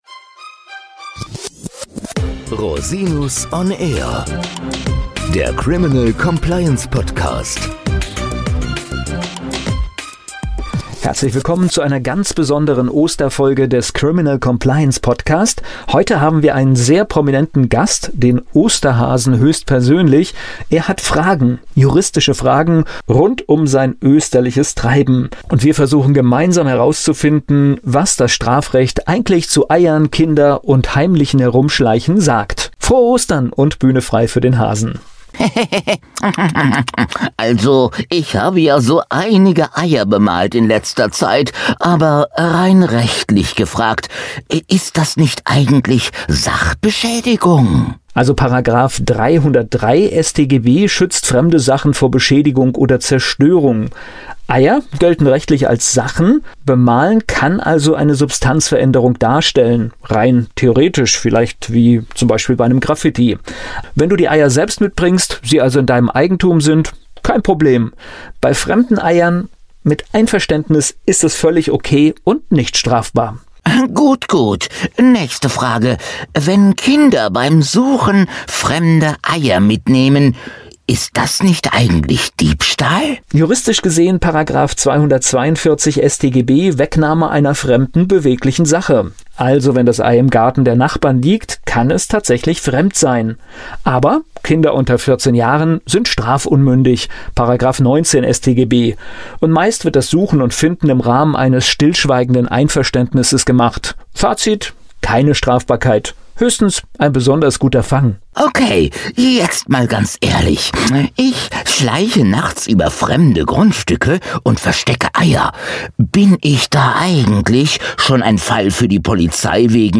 Interview mit dem Osterhasen